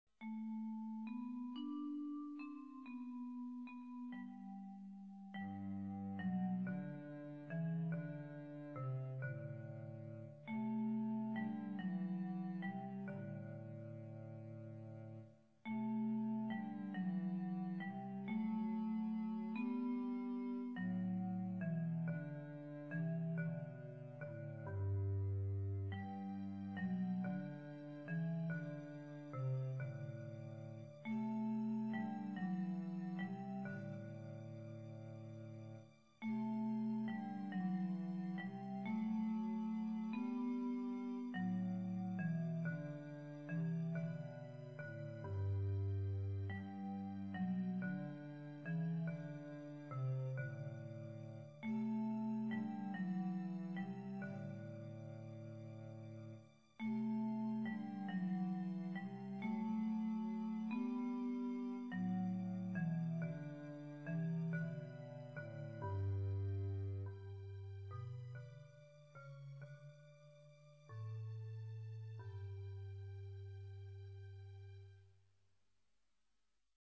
Everything is made in MIDI which gives a thinner sound.
alto voice in the cello